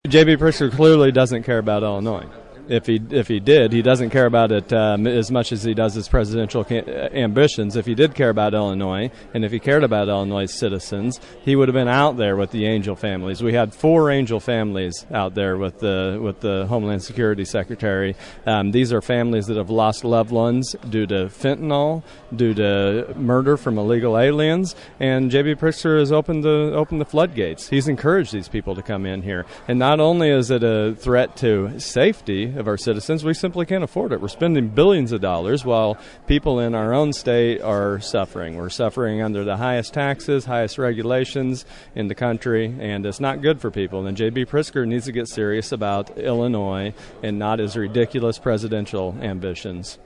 State Representative Blaine Wilhour spoke at the event, critical of Governor Pritkzer’s immigration policies.
As Wilhour mentioned, victims were on hand and spoke at the press conference.